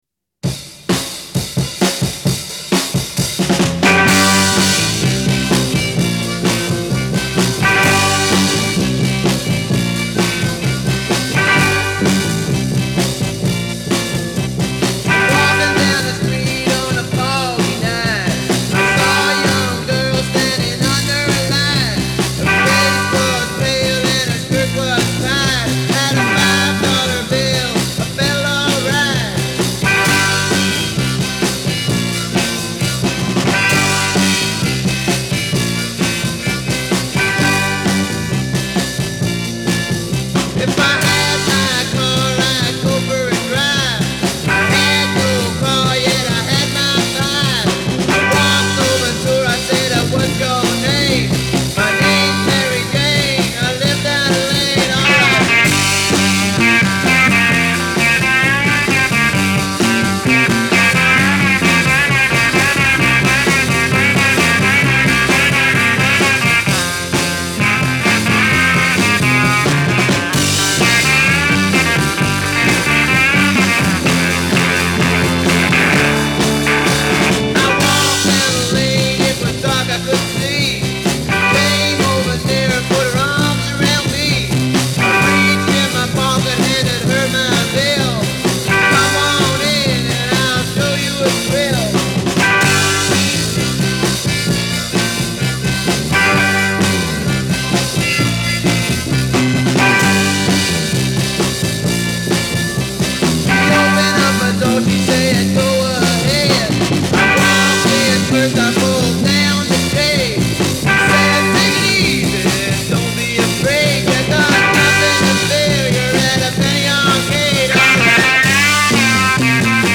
instrumental guitar band
instrumental guitar act